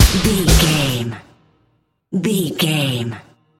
Epic / Action
Aeolian/Minor
Fast
drum machine
synthesiser
strings